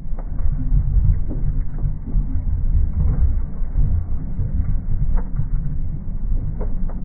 amb_pipe_loop_brokenloop.ogg